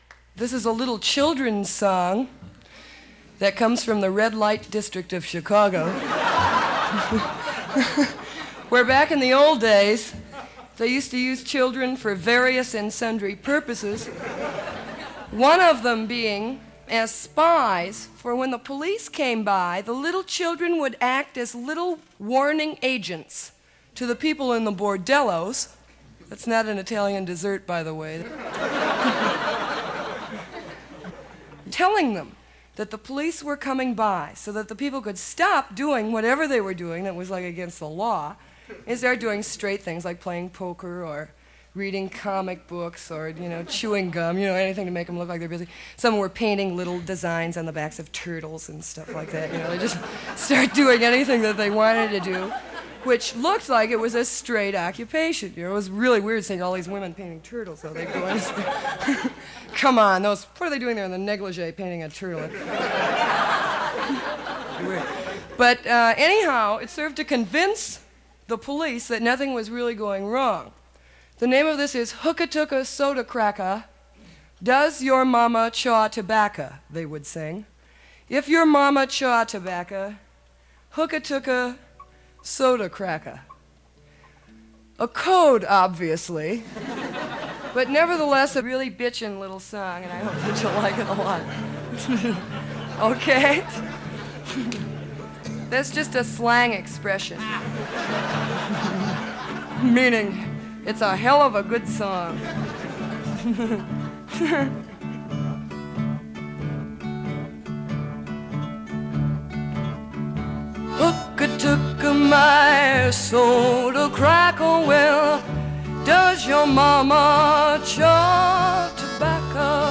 Here’s the song, with the intro dialogue: